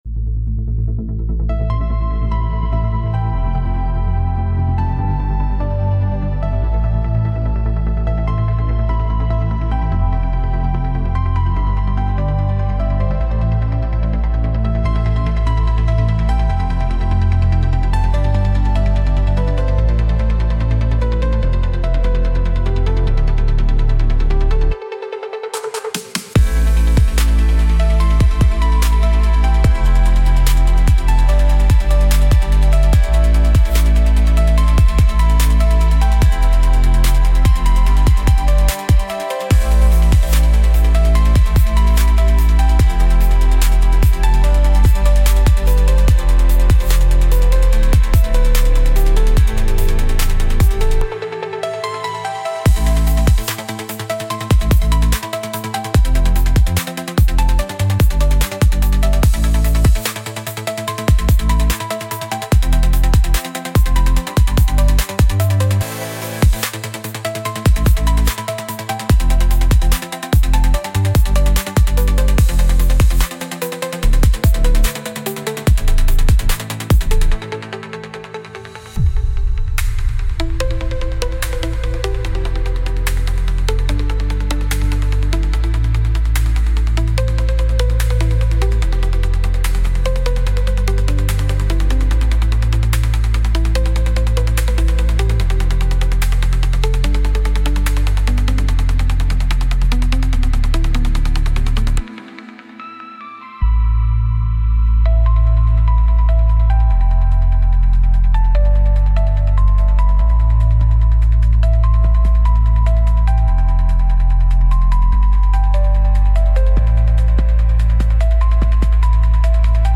Instrumental - Real Liberty Media DOT xyz -- 4 . min